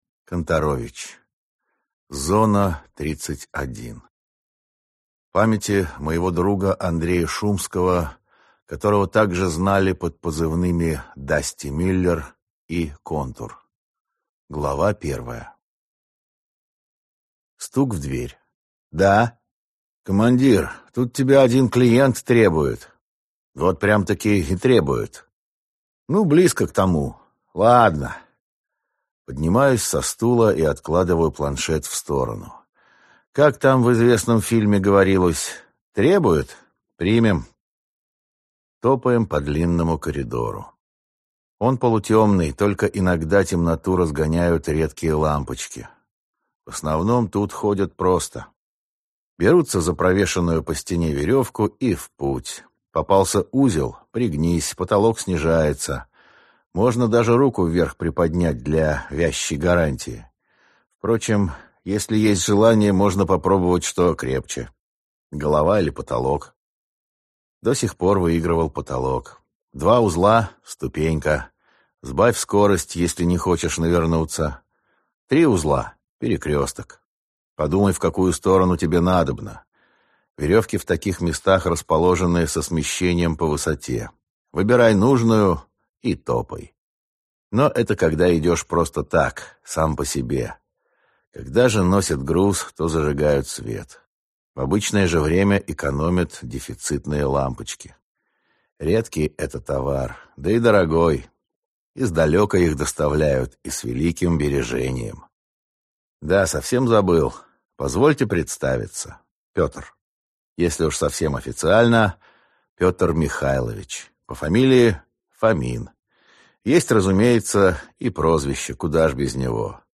Аудиокнига Спасатель | Библиотека аудиокниг